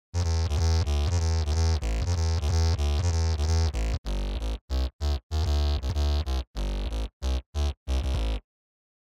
VSTi gitarový syntetizér
Ovladaju sa priamo audio signalom z gitary.
synth1.mp3